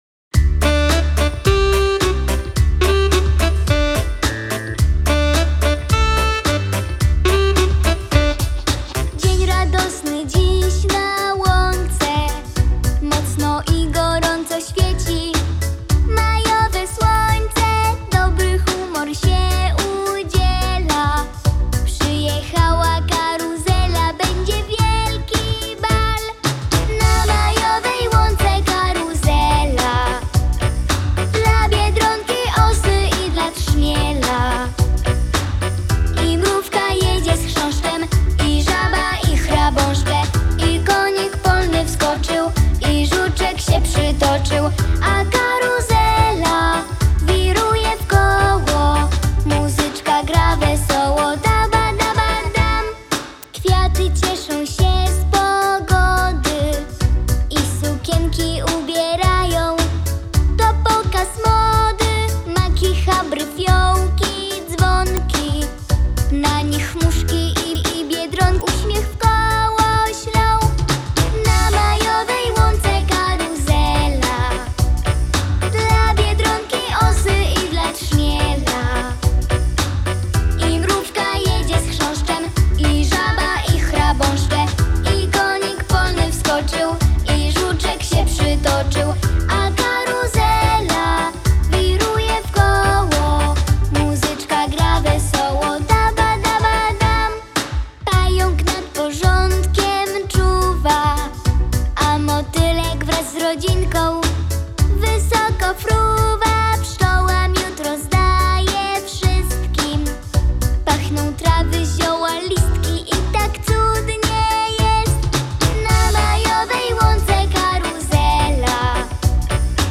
Piosenka